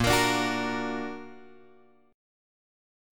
A# Augmented
A#+ chord {6 x 8 7 7 6} chord
Asharp-Augmented-Asharp-6,x,8,7,7,6.m4a